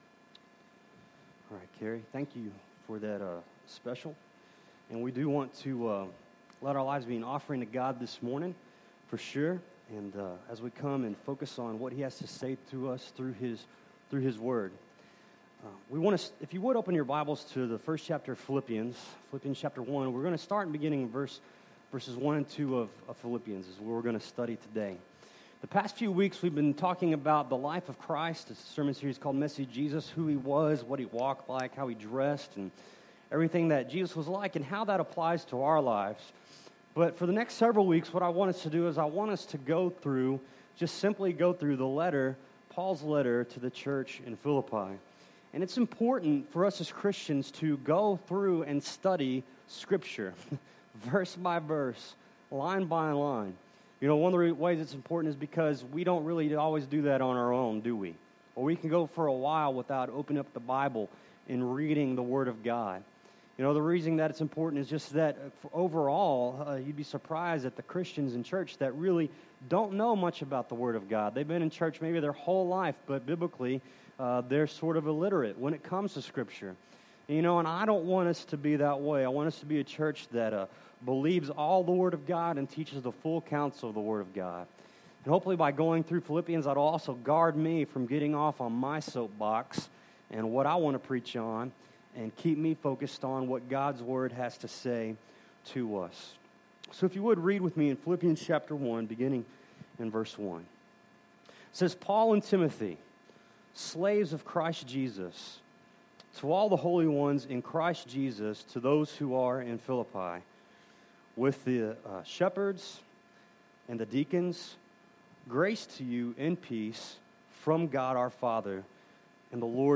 Passage: Philippians 1:1-2 Service Type: Sunday Morning